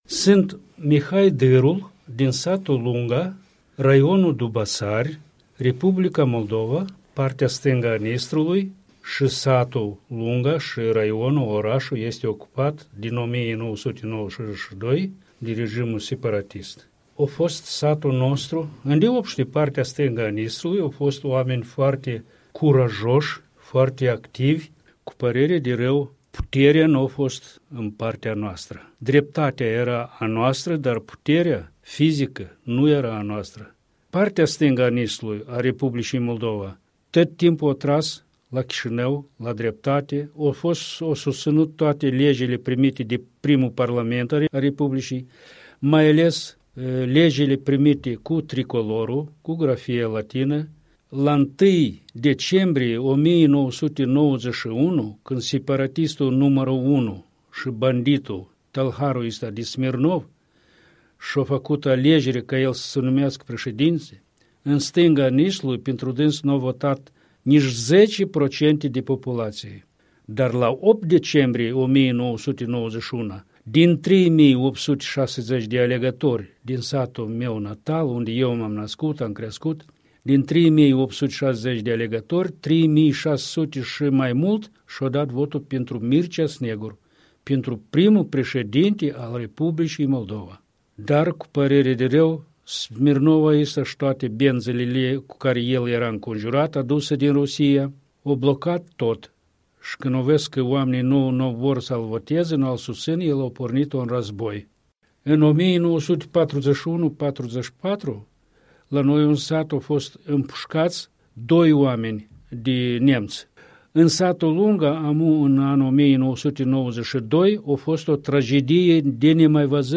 Interviu cu un fost deputat în Sovietul raional Dubăsari.
Un interviu cu Mihai Dârul